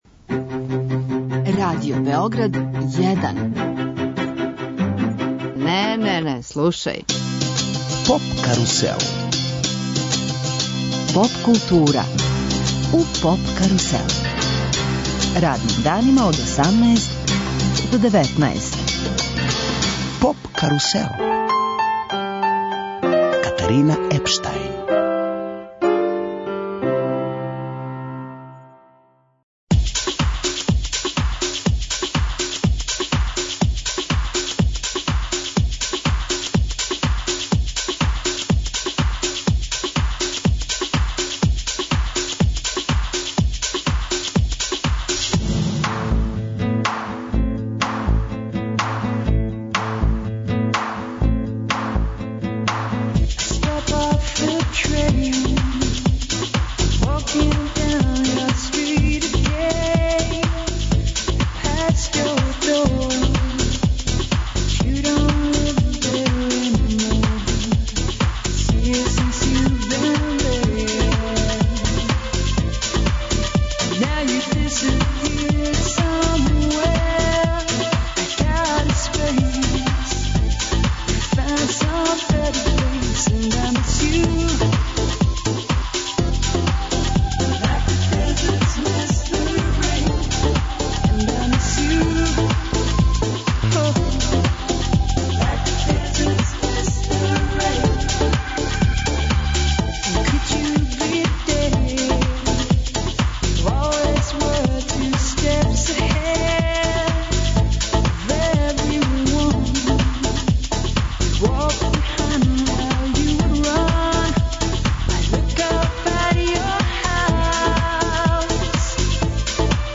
Гошћа емисије је ирска кантауторка Лиза Ханинген (Lisa Hanningan), поводом концерта, који ће да одржи у Дому омладине.